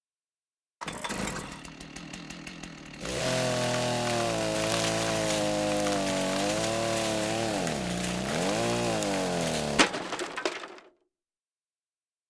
Chainsaw On Wood Wall